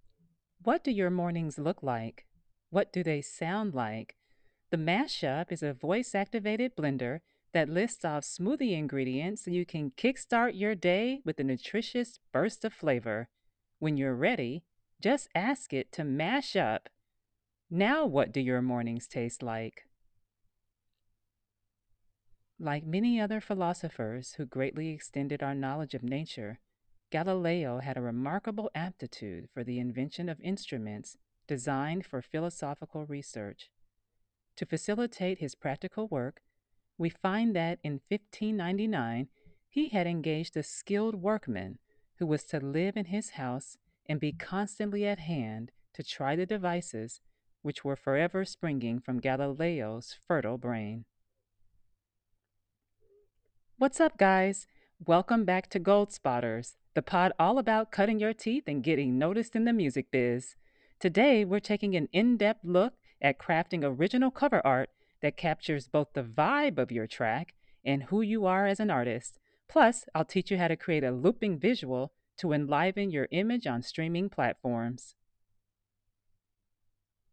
From warm and conversational to confident and cinematic, I deliver clear, engaging performances tailored to your project’s voice and vision.
Listen to my Voice Demo